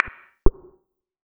pause-retry-click.wav